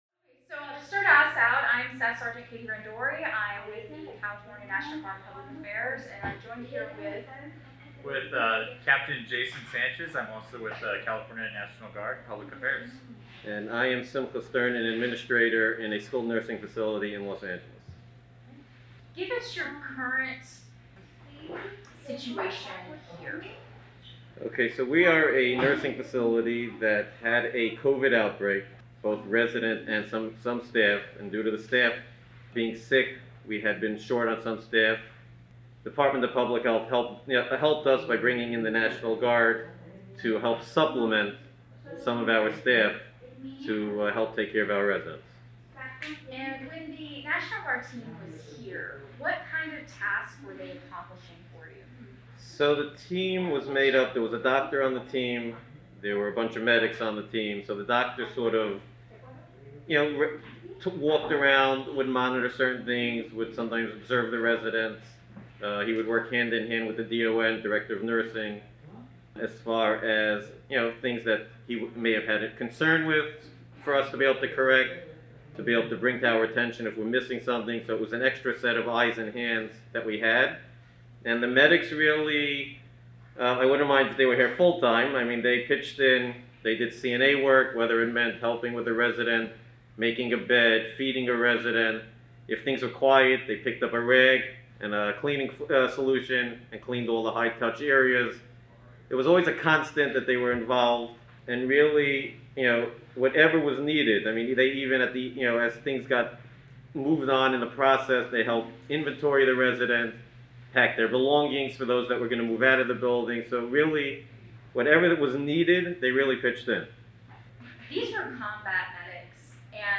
Audio interview with skilled nursing facility administrator about Cal Guard assistance during COVID-19 pandemic